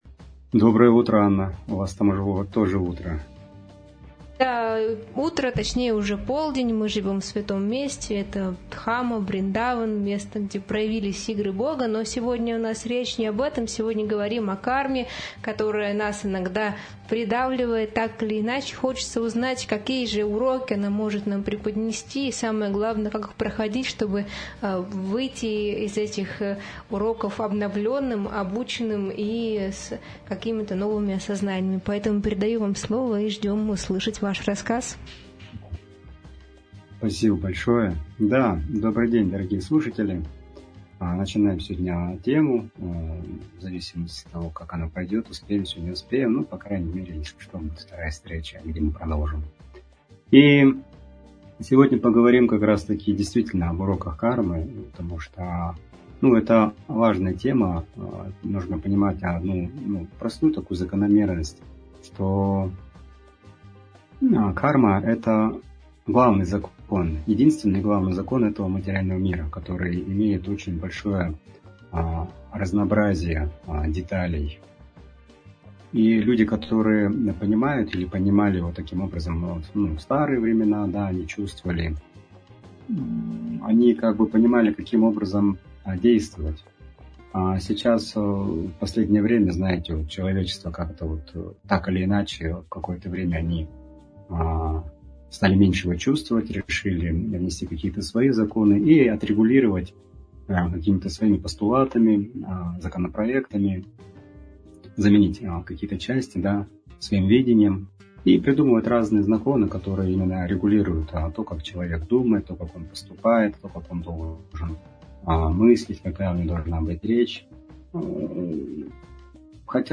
Эфир посвящён теме кармы как основного закона жизни, регулирующего причинно-следственные связи. Обсуждаются свобода выбора, неизбежность последствий, личная ответственность и доброжелательность.